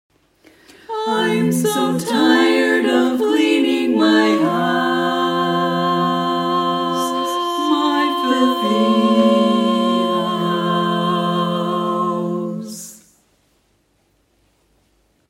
Key written in: F Major
How many parts: 4
Type: Female Barbershop (incl. SAI, HI, etc)